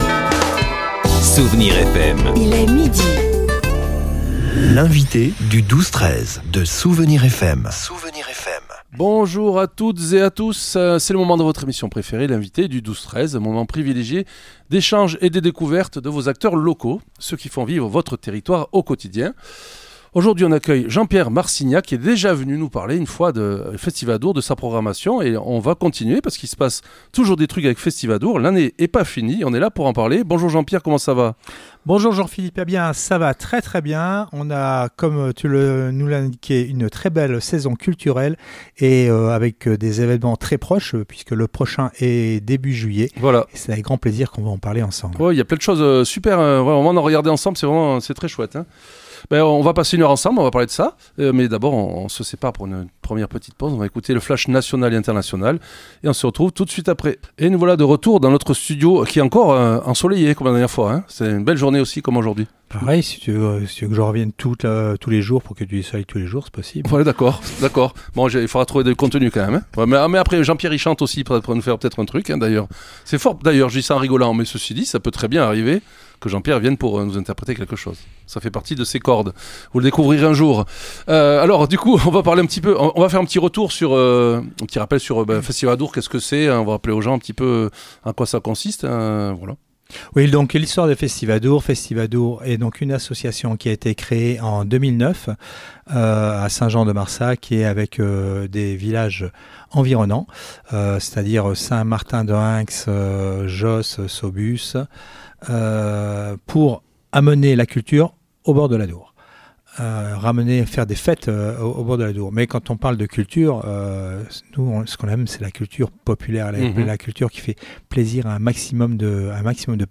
L'invité(e) du 12-13 de Soustons